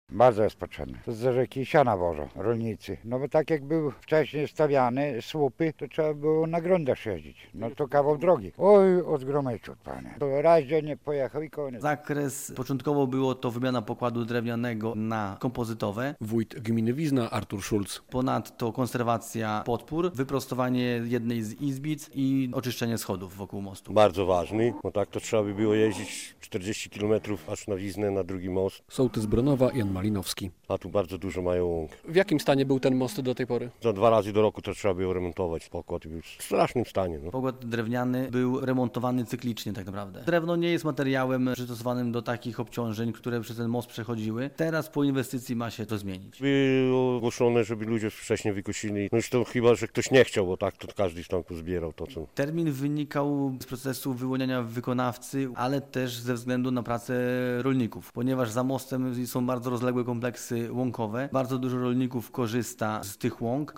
Remont rozpoczął się teraz właśnie ze względu na cykl pracy rolników - mówi wójt gminy Wizna Artur Szulc.